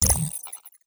Robotic Game Notification 11.wav